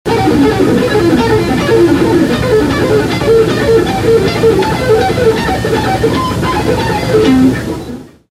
SHRED PICKING: Alternate & Sweep Picking
The MP3s of the two fast-picking lines I'm presenting below were taken from one of a zillion old tapes I have of me trying to blaze...
The audio quality is pretty bad, but at least you can hear the stuff.
Sweep Picking Lick
ShredPicking(SWEEP)1995.mp3